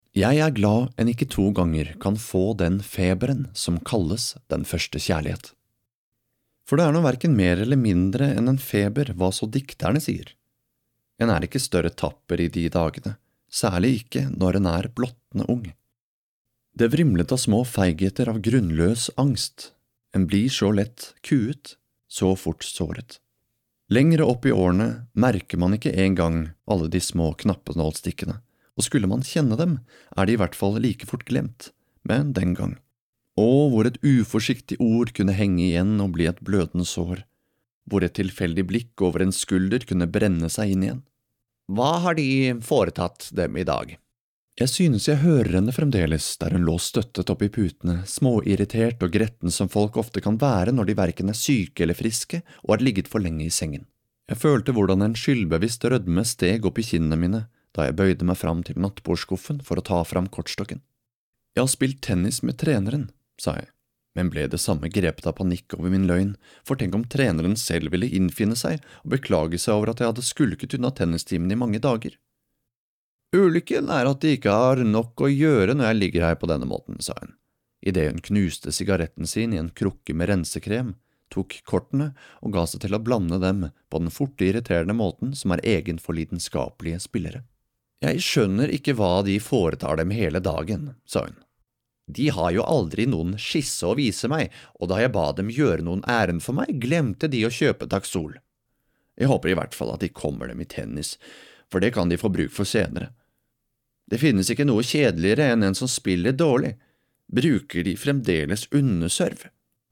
uma voz barítono norueguesa, profunda e acolhedora
Audiolivros
Mic: Shure SM7B + Trtion Audio Fethead Filter
BarítonoProfundoBaixo